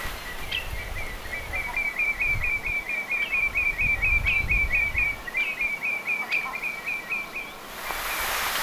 valkoposkihanhi "kotkottaa"
Ääntä kuului paljon lintutorniin,
kun parvi ruokaili.
valkoposkihanhen_kotkot-aani.mp3